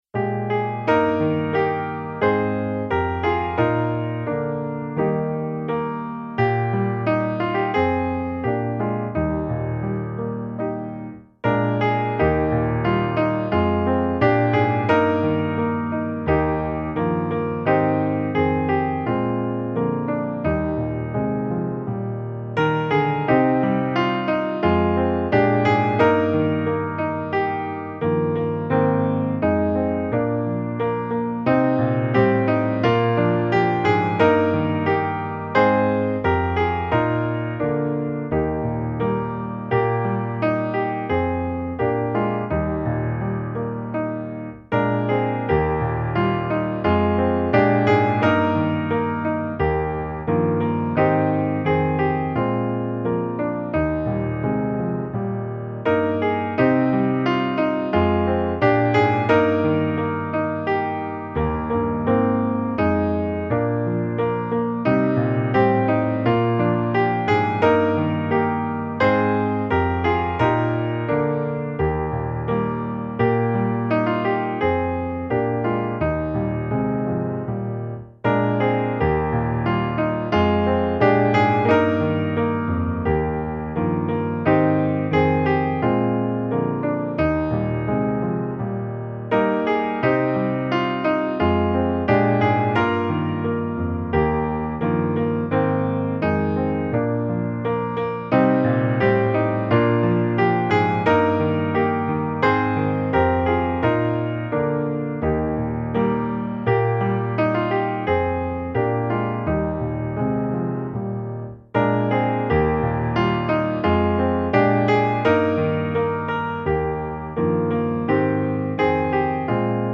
Musikbakgrund Psalm